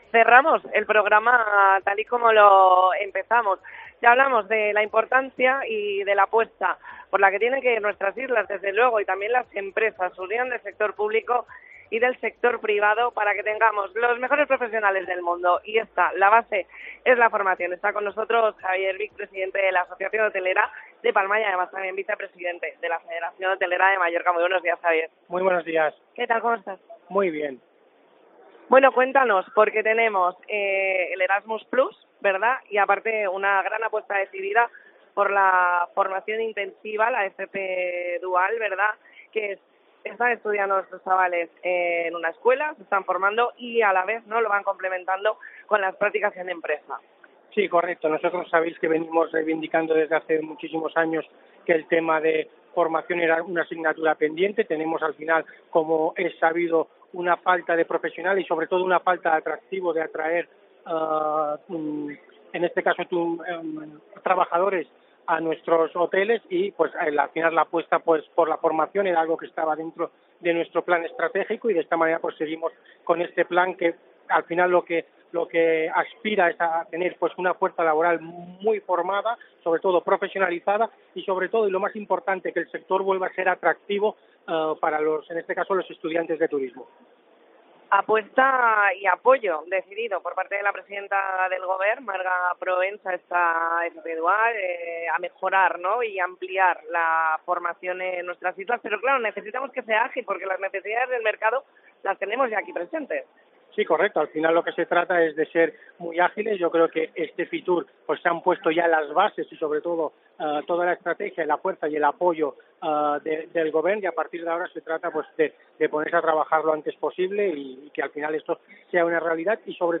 Especial COPE Baleares desde FITUR
Entrevista